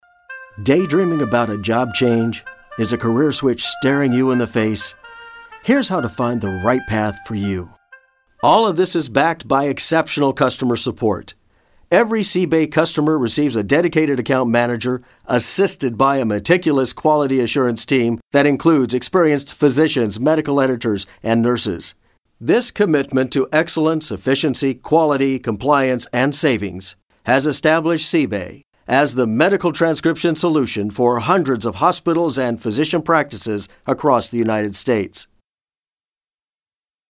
englisch (us)
Sprechprobe: Industrie (Muttersprache):